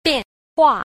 bian_hua.mp3